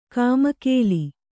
♪ kāmakēli